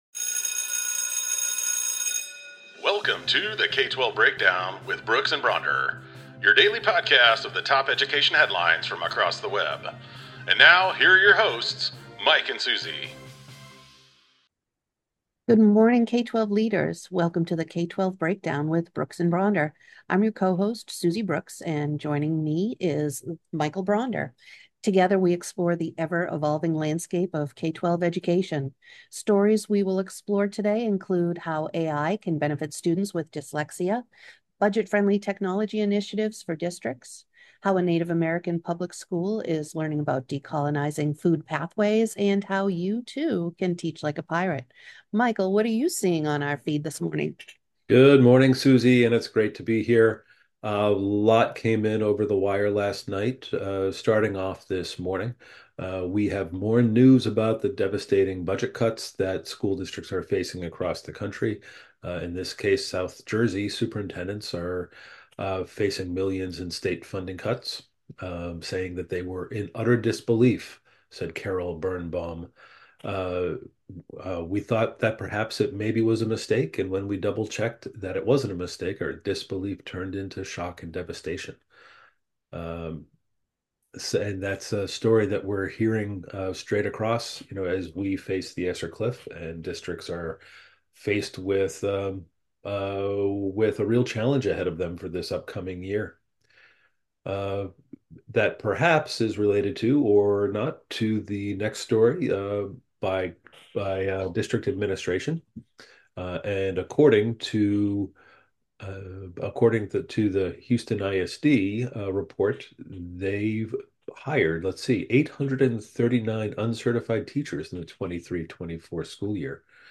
We readout and breakdown the top education headlines from across th